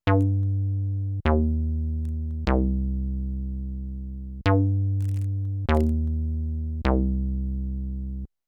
I’m noticing a fairly persistent scratching sound. I tried both the 1/4 inch output as well as the phones output and it’s present in both.
Distortion is definitely off.
The noise only seems to occur when the VCA is active and it almost disappears when I turn the cutoff/decay up all the way and resonance/envelope/accent down all the way.
Tried with JUST the headphones in the main output, phones output, as well as the main output into my Scarlett 2i2 (volume knob on td-3 at 12 o’clock) to record the noise.